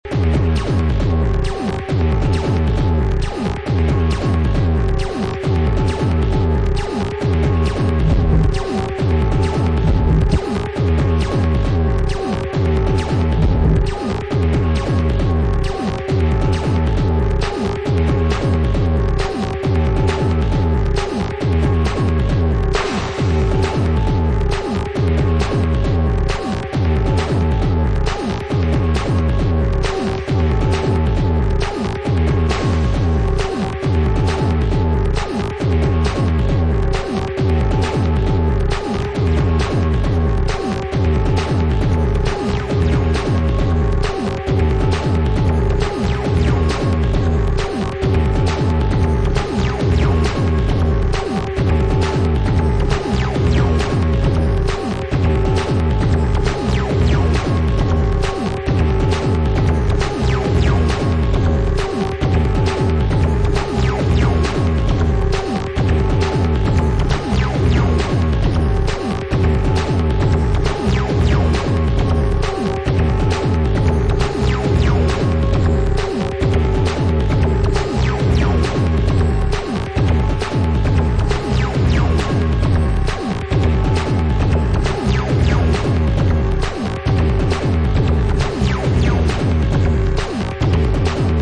IDM/Electronica, Electro, Techno